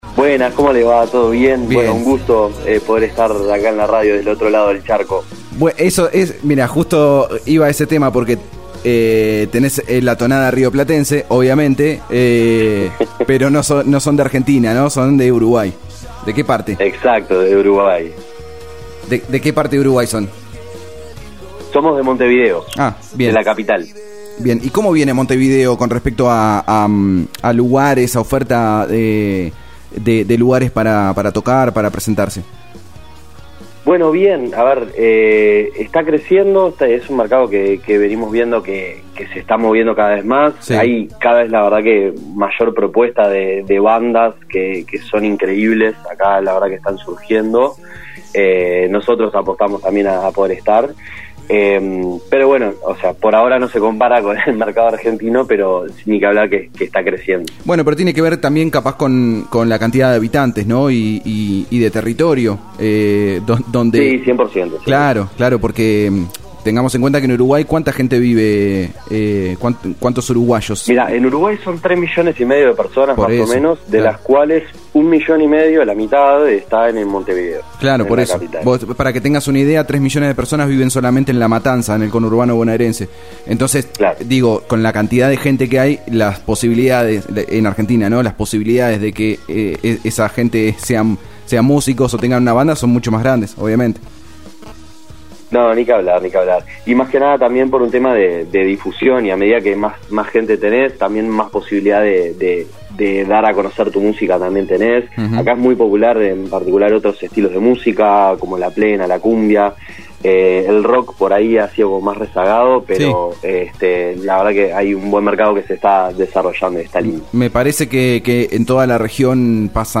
Notas y Entrevistas realizadas en Om Radio